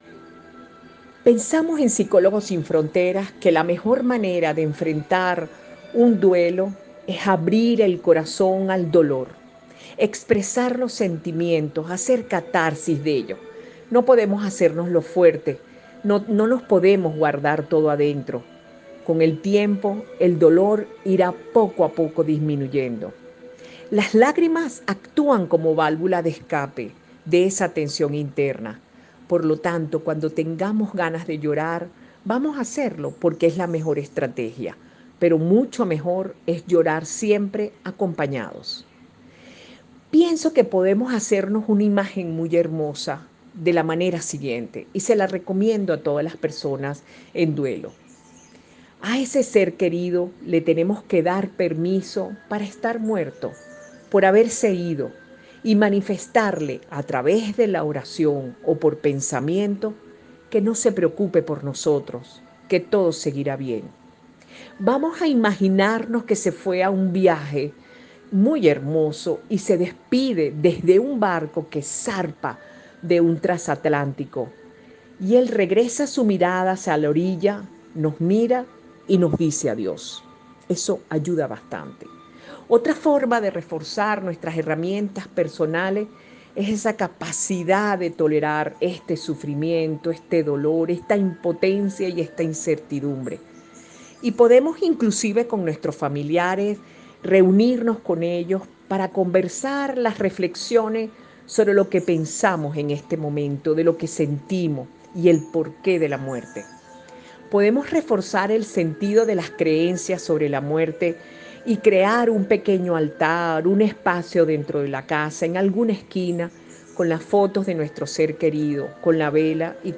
A continuación les presentamos los audios con su ponencia.